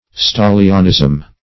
stahlianism - definition of stahlianism - synonyms, pronunciation, spelling from Free Dictionary
stahlianism.mp3